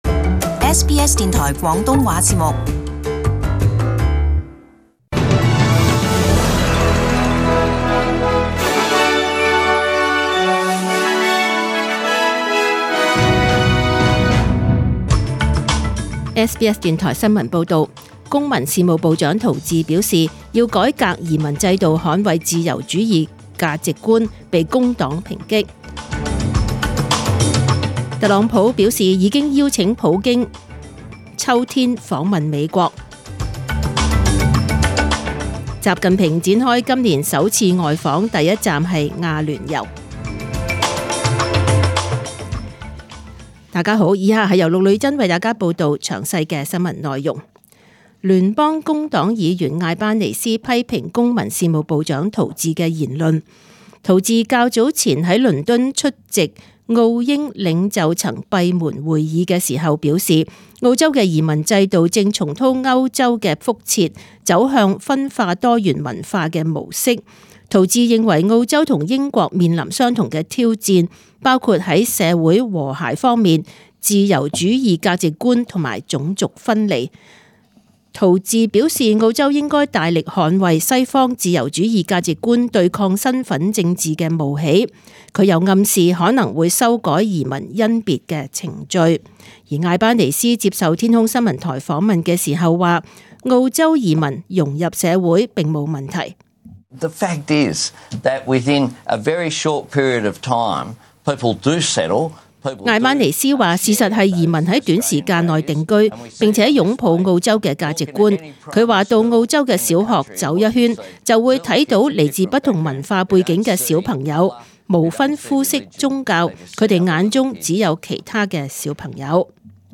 SBS中文新闻 （七月二十日）
请收听本台为大家准备的详尽早晨新闻。